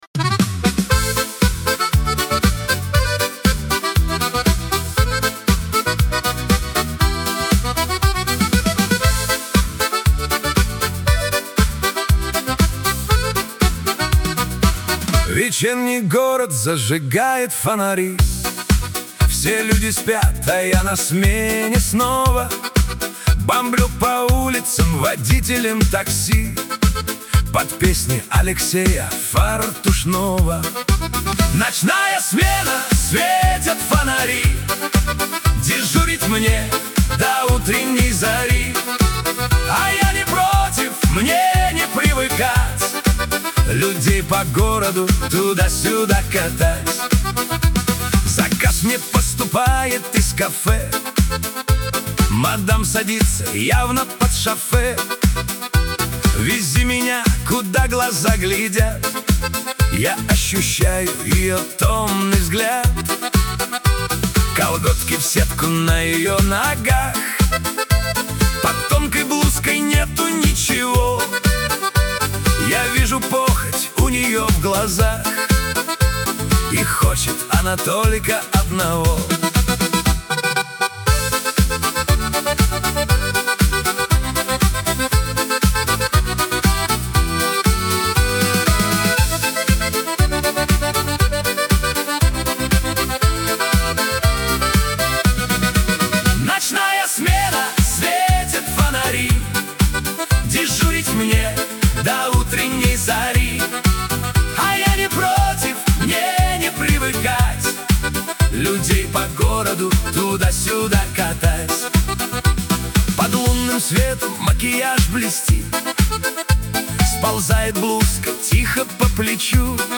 pop
Шансон , Лирика